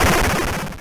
break.wav